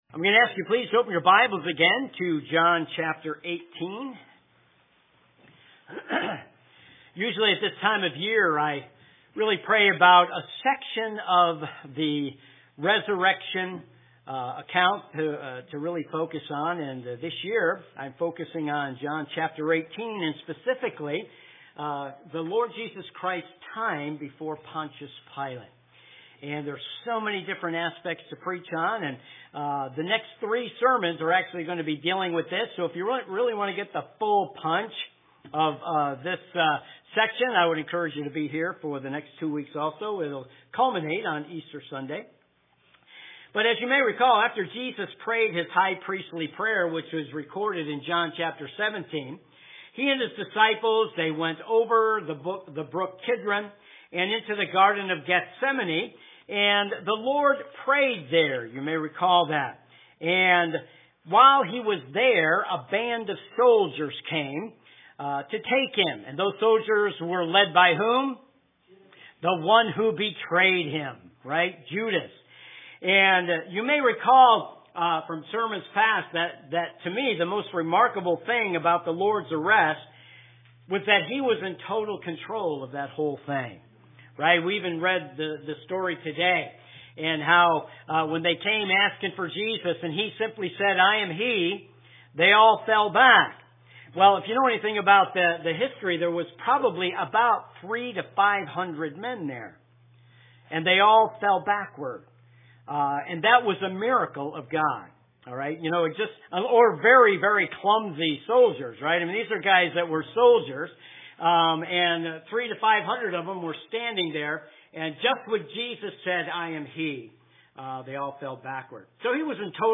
We are an independent, Baptist church located in Oakdale, CT ministering to Norwich, New London, Groton, Montville, Uncasville and Quaker Hill.